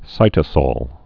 (sītə-sôl, -sŏl)